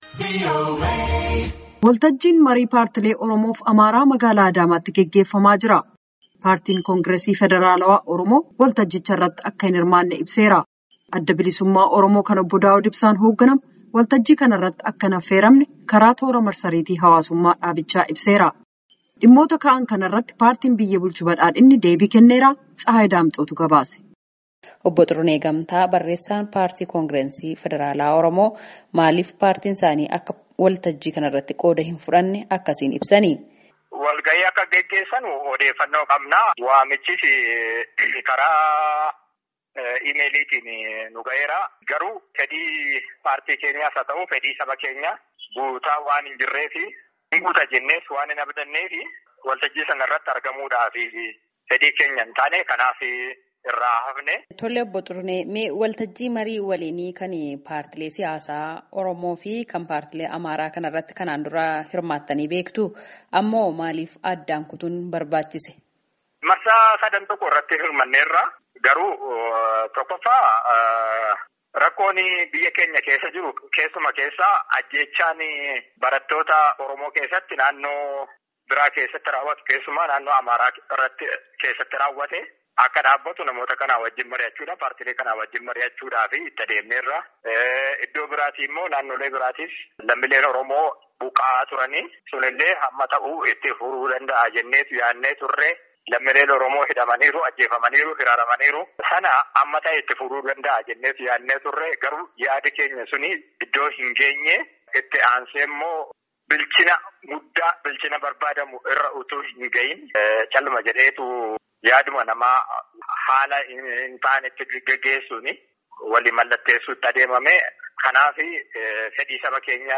Dhimmoota marii kana irratti hoogganoota paartiilee mormituu fi ka paartii biyya bulchuus dubbisnee jirra.